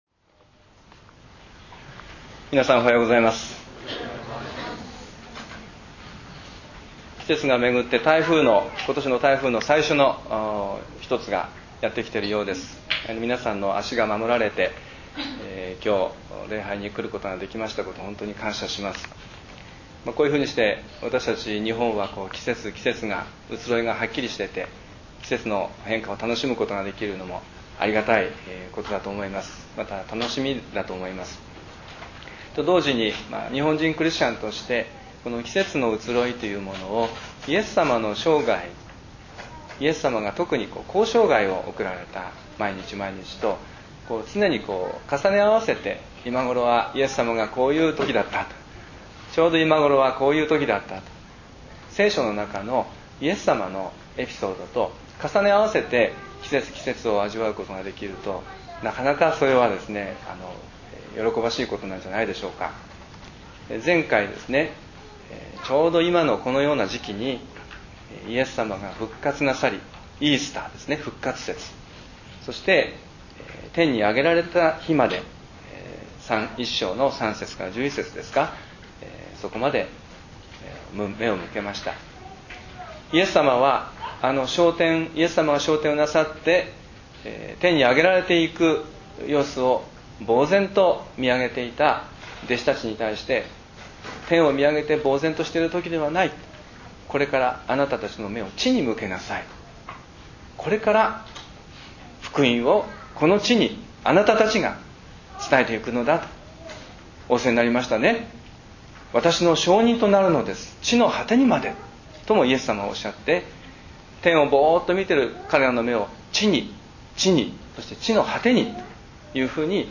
礼拝宣教録音 － 復活の証人